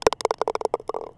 golf_target.wav